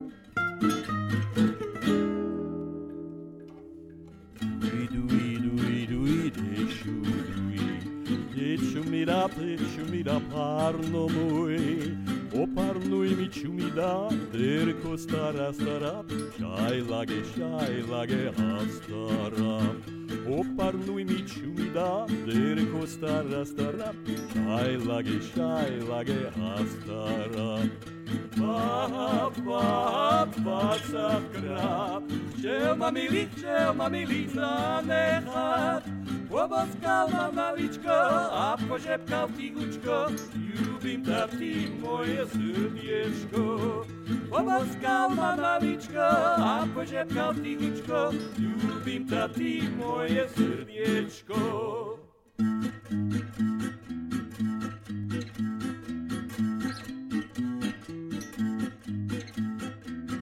Duj slovak vocals rough mix.mp3
😁 We have two kinds of "l"....A hard l and a soft ľ and he's doing something in-between
Really nice! love the warm tone of the vocals
duj-slovak-vocals-rough-mix.mp3